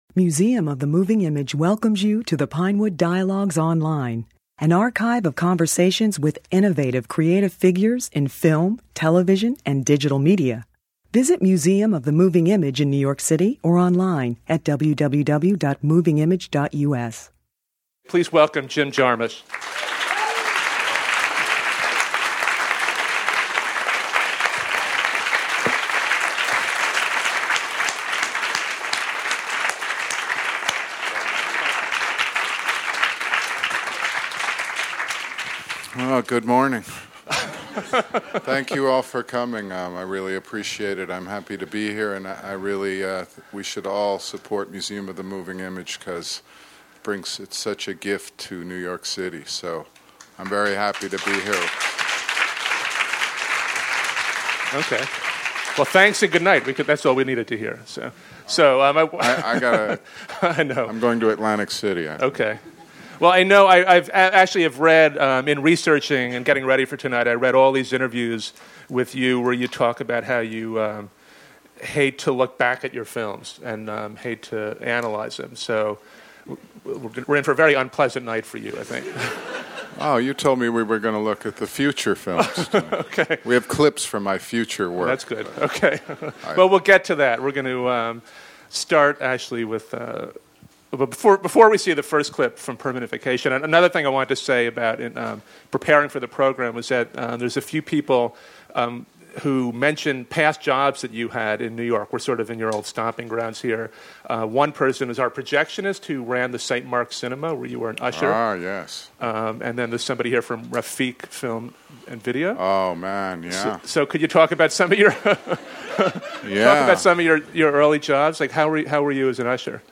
To celebrate the release of his remarkable movie The Limits of Control, the Museum of the Moving Image presented an evening with Jim Jarmusch. The director talked about his entire body of work, starting with his NYU student feature-length film Permanent Vacation.
This raw audio includes the film clips in their entirety.